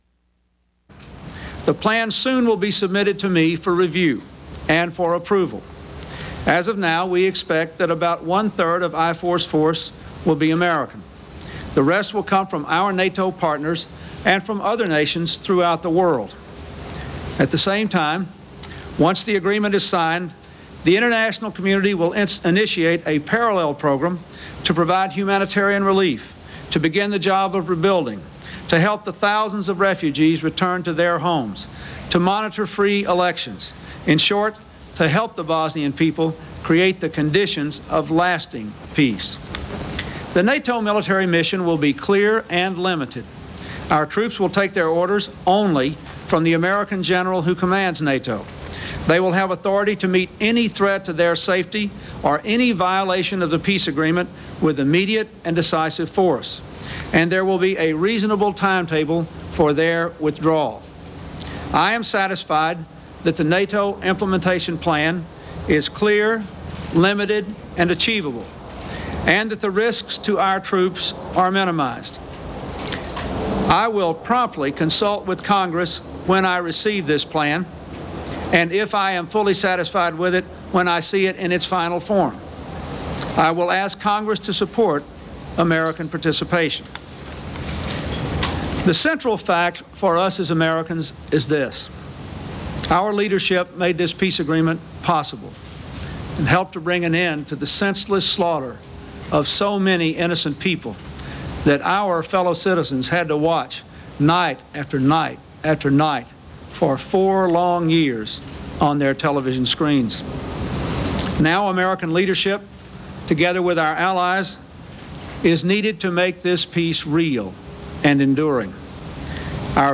U.S. President Bill Clinton announces an agreement among the warring forces in Bosnia